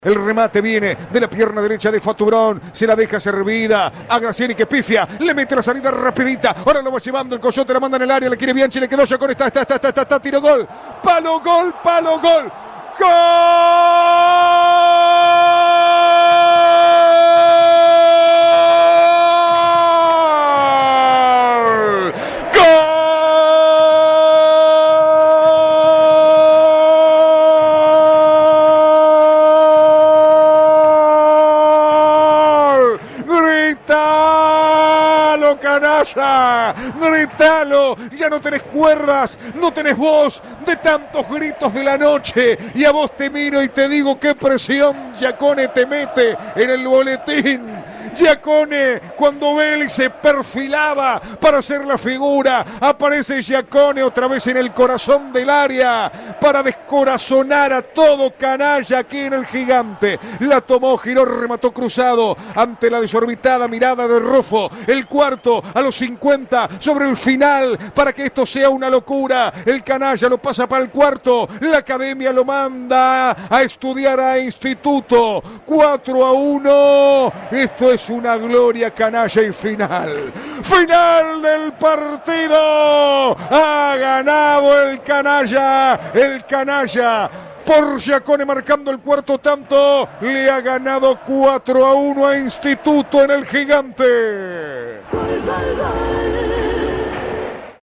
4º gol de Rosario Central a Instituto (Giaccone) - relato